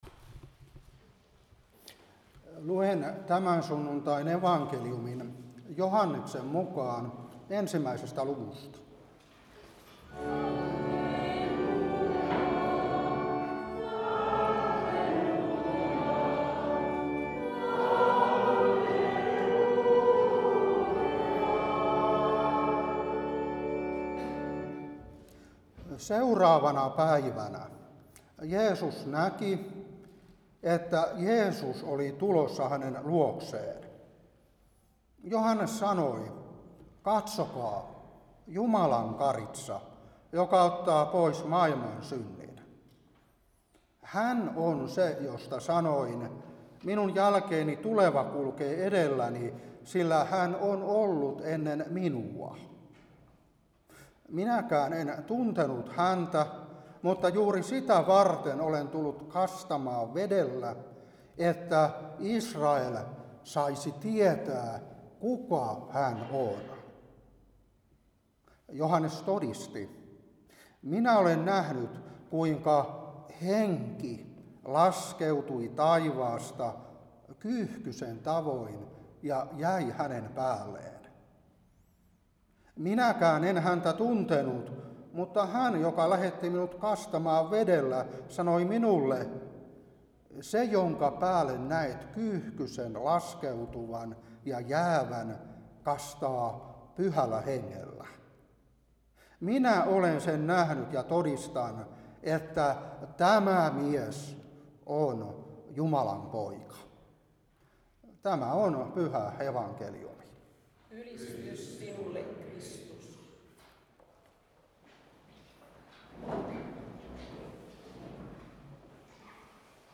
Saarna 2026-1.